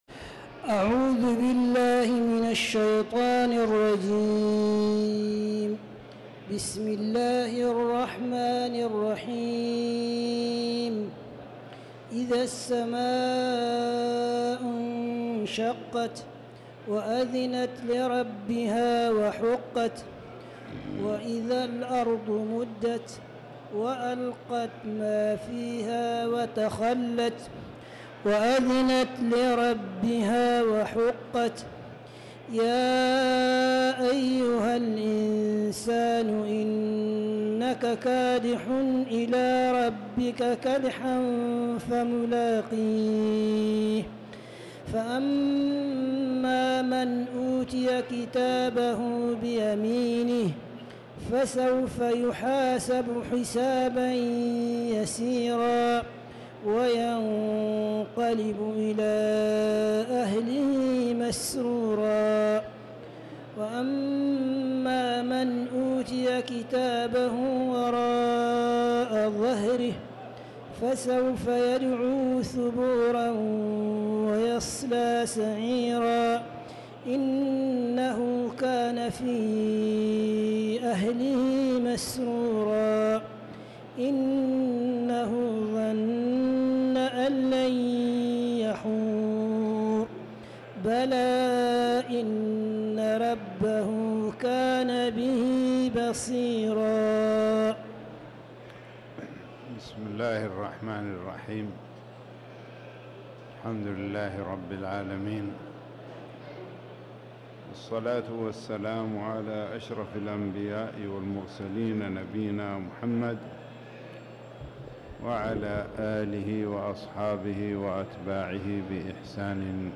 تاريخ النشر ٢٤ شعبان ١٤٤٠ هـ المكان: المسجد الحرام الشيخ